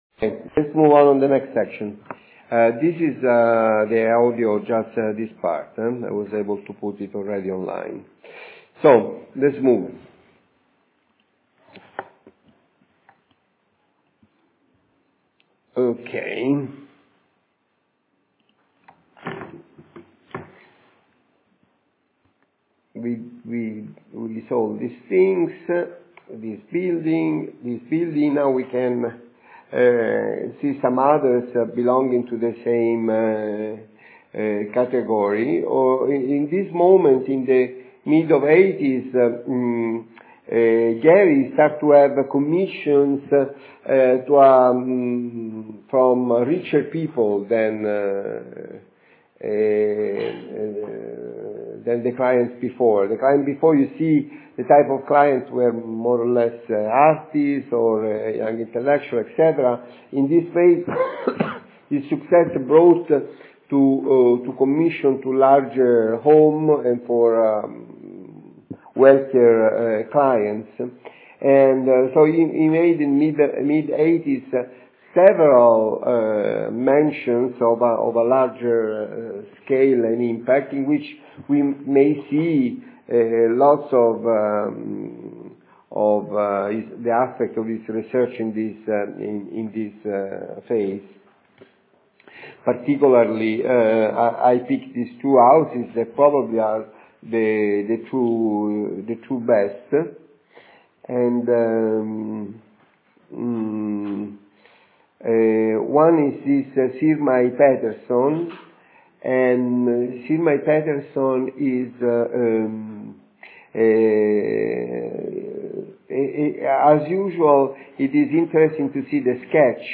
Master Lecture and Workshop 2015 Six Design Operations by Frank Owen Gehry